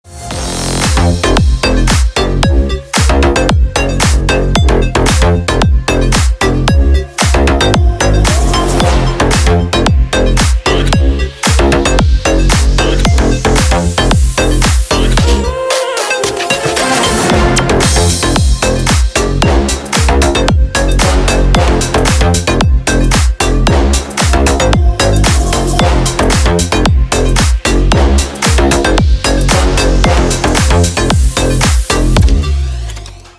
• Категория: Рингтоны
громкие рингтоны 2022 скачать на телефон онлайн.